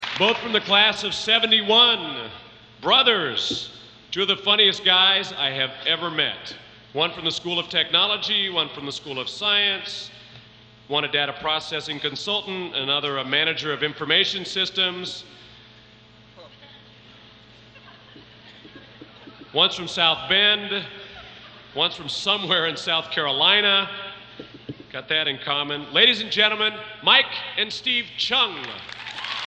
Collection: Centennial Celebration Concert 1993
Genre: | Type: Director intros, emceeing